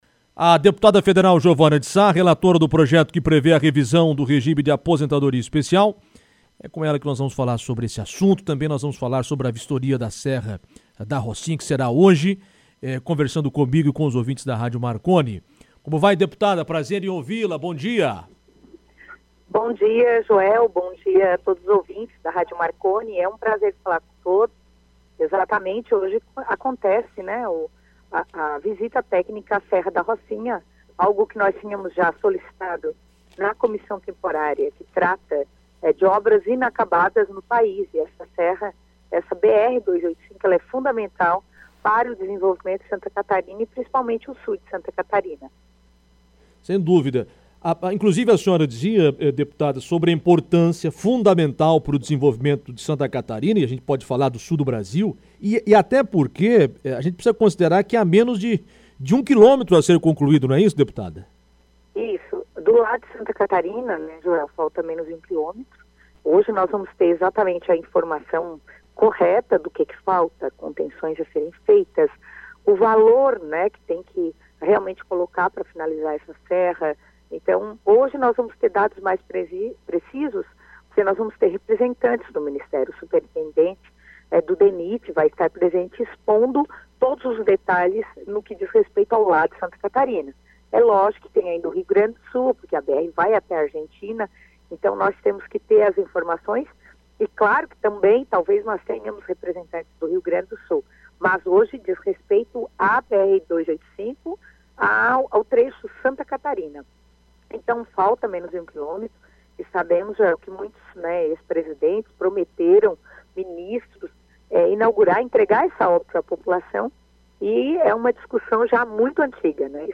Assunto foi abordado em entrevista, entenda: